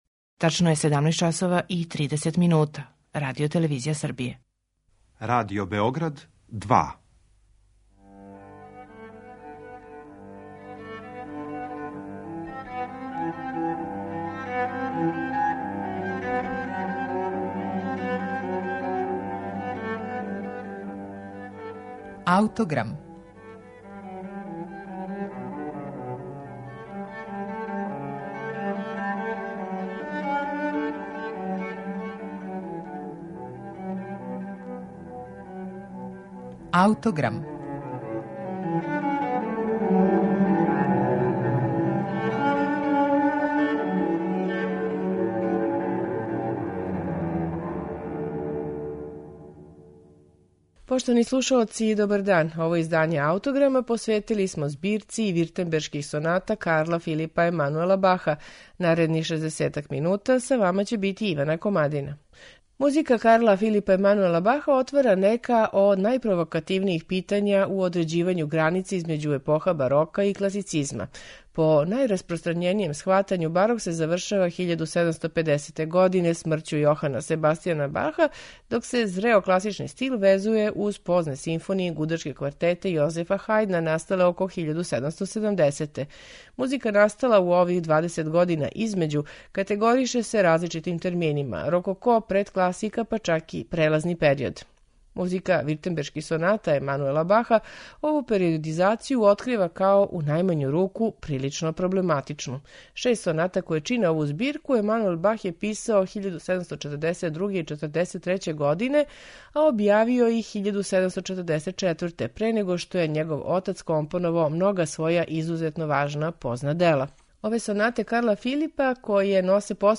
Сонате су посвећене војводи од Виртемберга, Баховом некадашњем ученику, а писане су за чембало.
У данашњем Аутограму „Виртембершке сонате" Карла Филипа Емануела Баха слушаћете у интерпретацији чембалисте Махана Есфаханија.